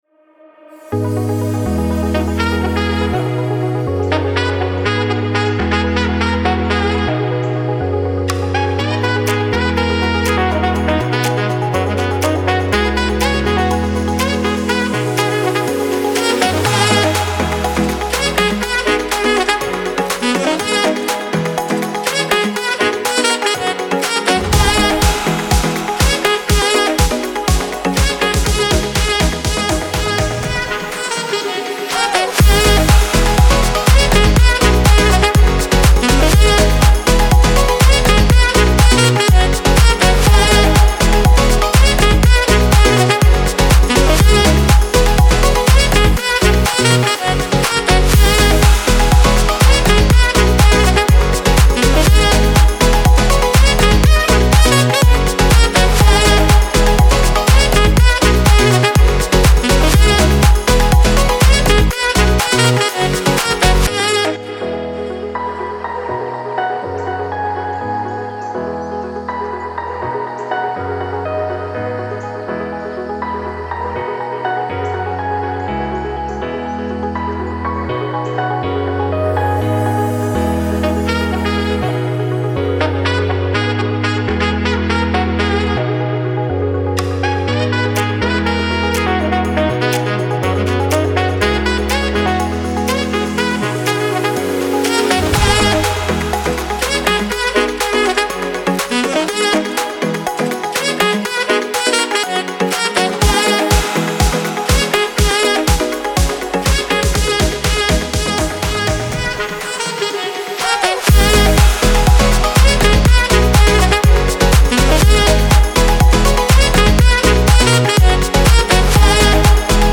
موسیقی بی کلام الکترونیک پاپ موسیقی بی کلام انرژی مثبت